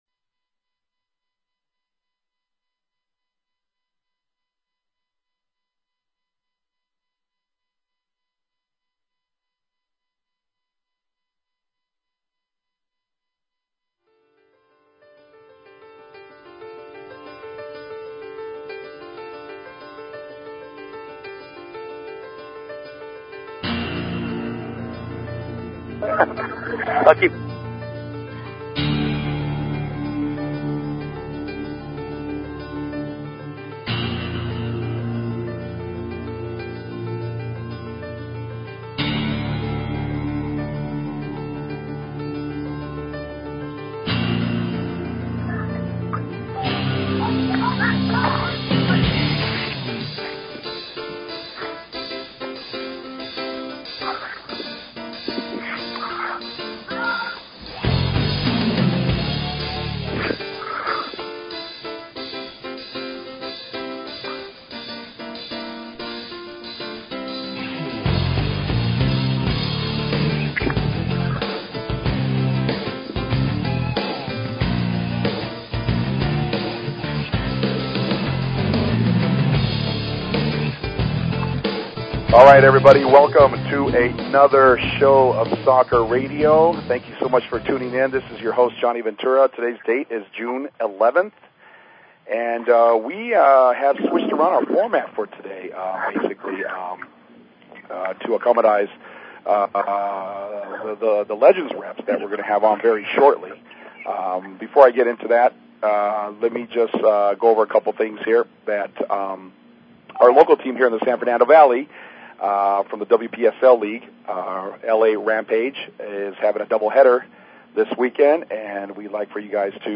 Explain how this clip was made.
Guest appearances by players, coaches and live phone calls are also part of the shows format.